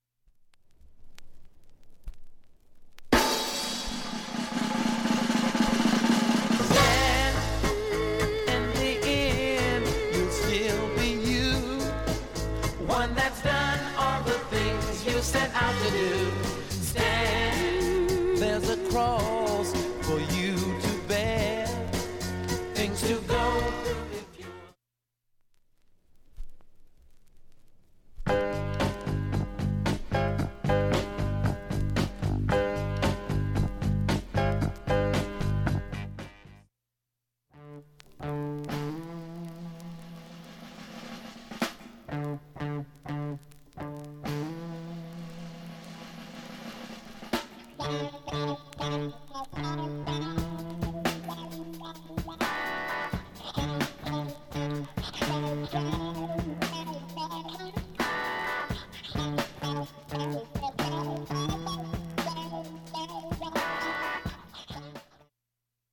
音質良好全曲試聴済み。
チリもほとんど無し
始めの数回ボッというかすかな周回音が出ますが
ほか単発のかすかなプツが１２箇所